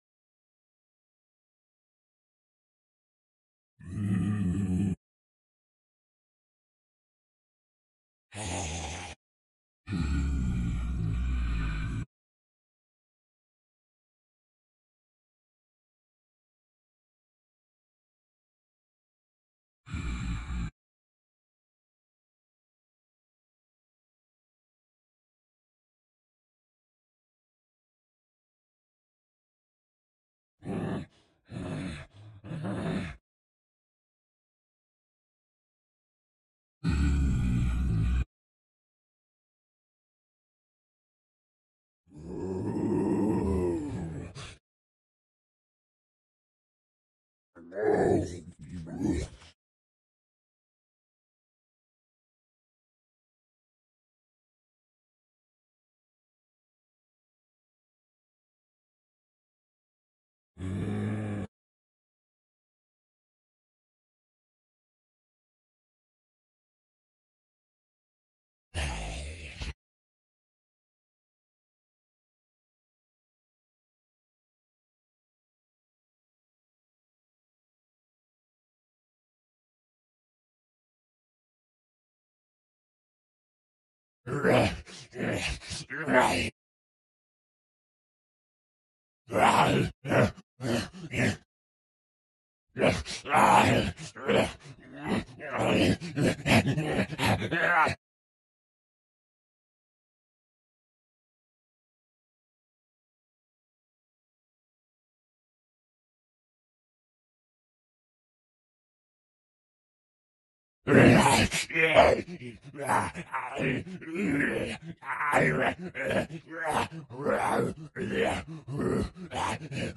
Kyma僵尸 " 僵尸Kyma表演05 - 声音 - 淘声网 - 免费音效素材资源|视频游戏配乐下载